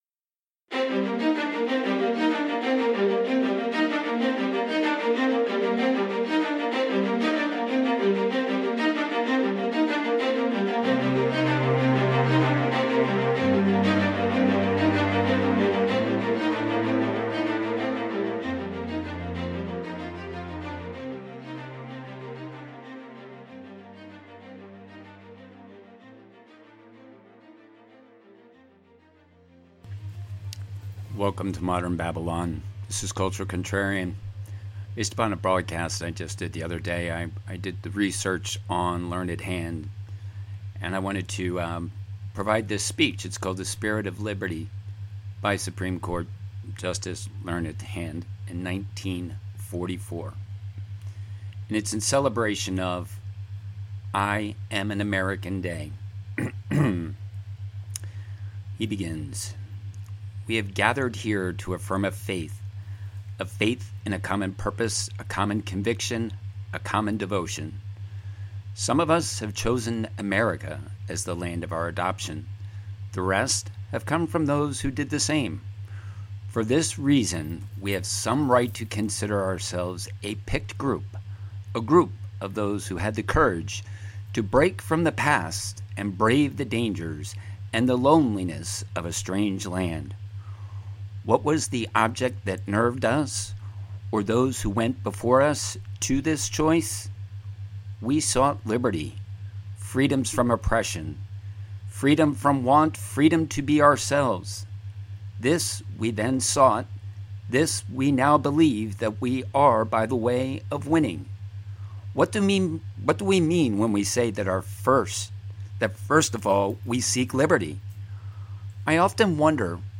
Speech from Justice Learned Hand 1944